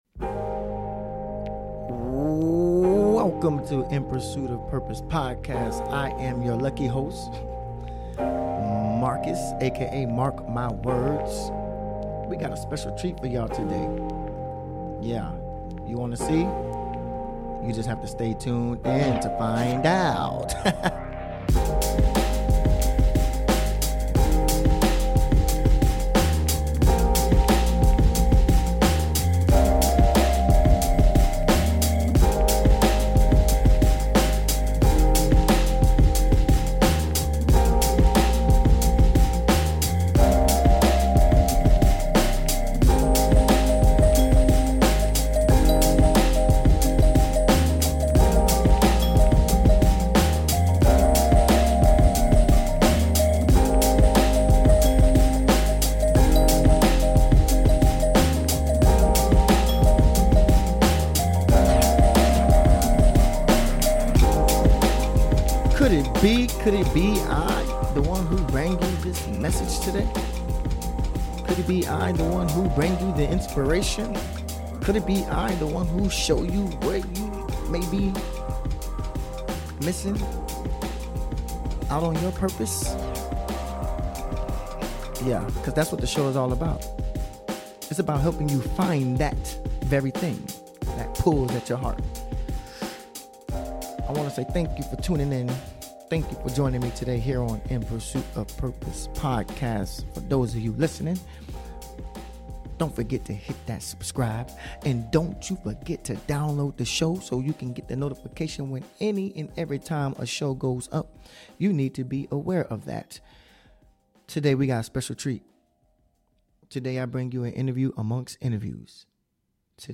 Today on the show we interview